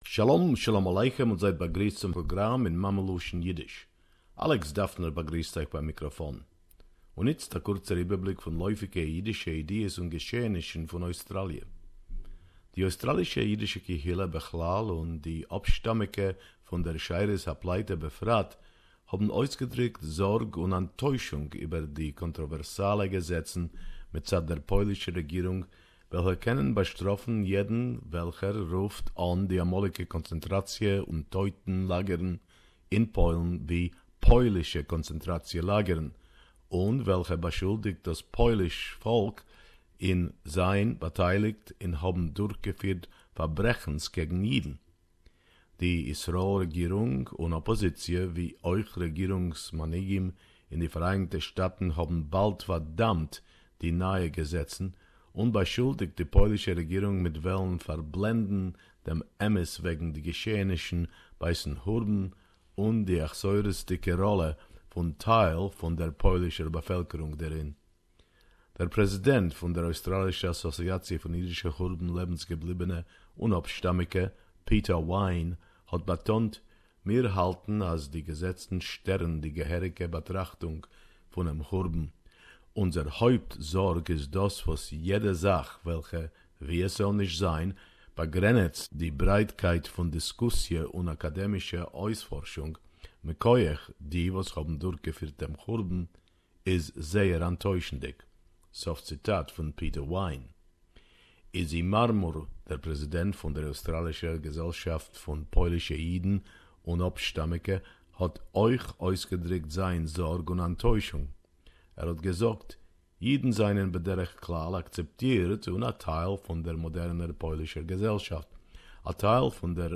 Don't miss our weekly Yiddish report: